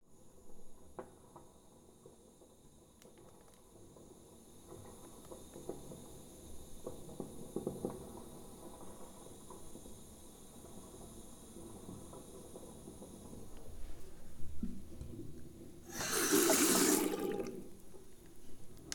file0233-hiss,water
Category 🌿 Nature
bath bubble burp click drain dribble drip drop sound effect free sound royalty free Nature